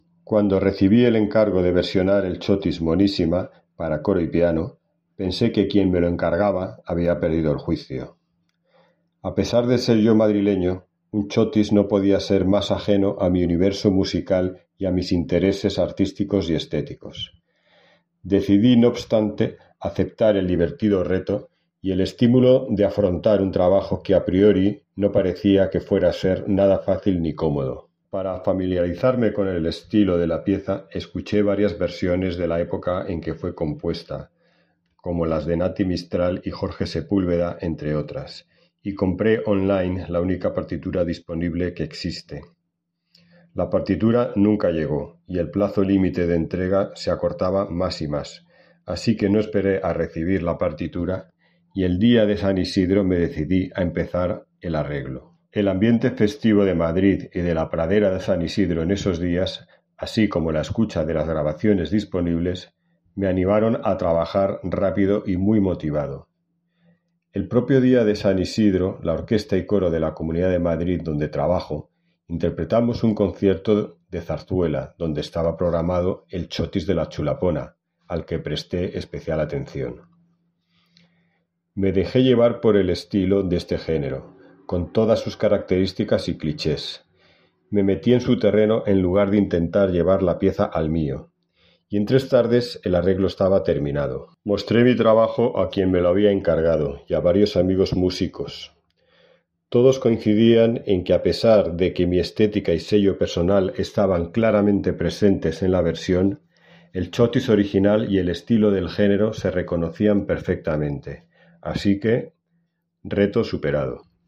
Audios con las declaraciones